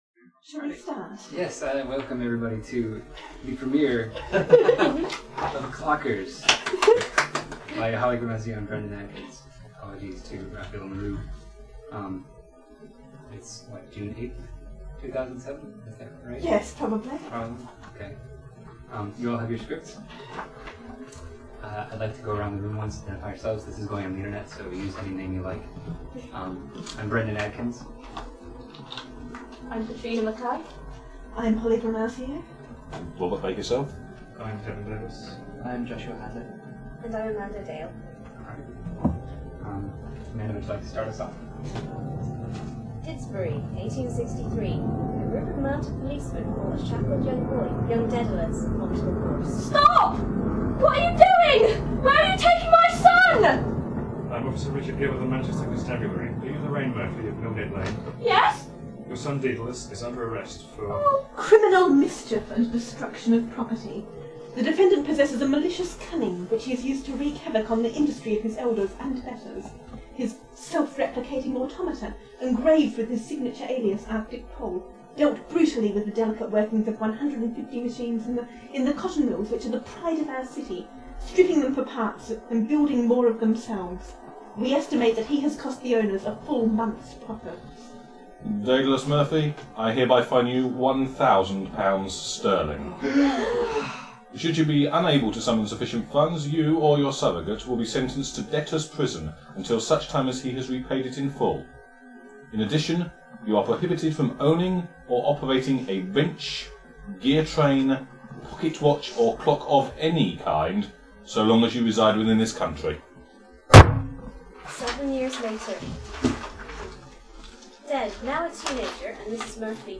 Read aloud as a podplay on June 8